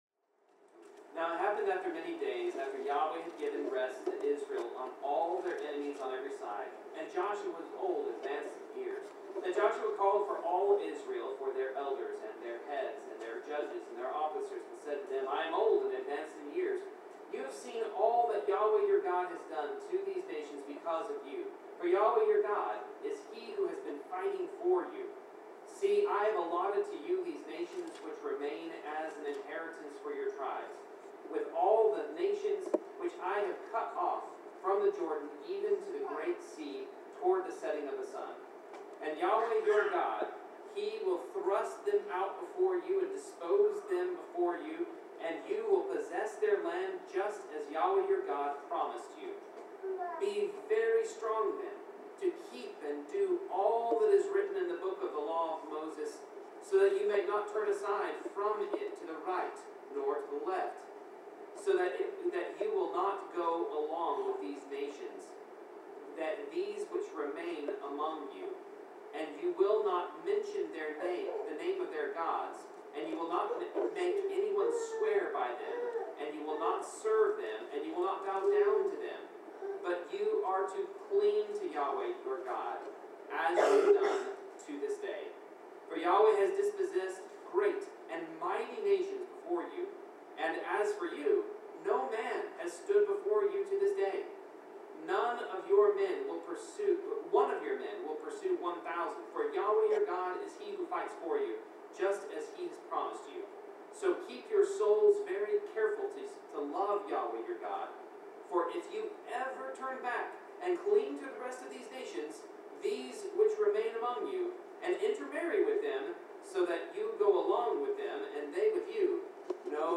Bible class: Joshua 23-24
Service Type: Bible Class Topics: Blessings , Consequences of Sin , Curses , Faith , Idolatry , Obedience , Promises of God , Prophecy , Trusting in God , Wrath of God